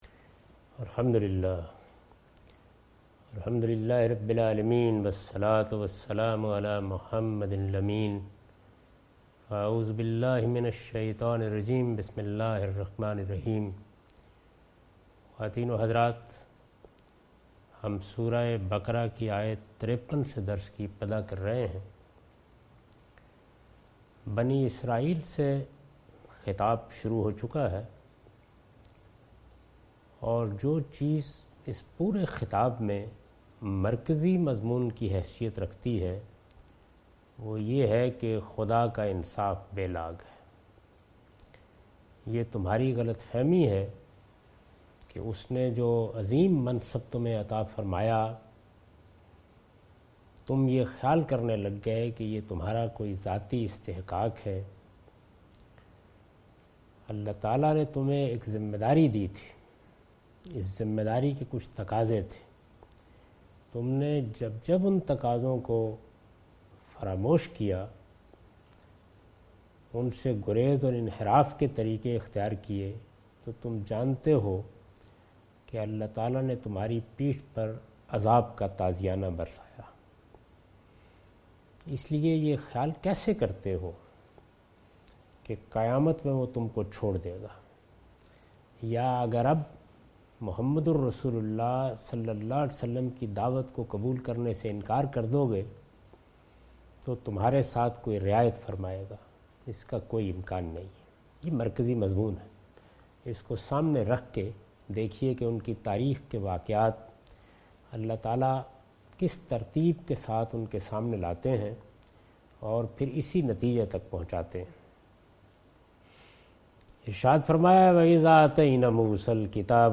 Surah Al-Baqarah - A lecture of Tafseer-ul-Quran – Al-Bayan by Javed Ahmad Ghamidi. Commentary and explanation of verse 53 and 54 (Lecture recorded on 30th May 2013).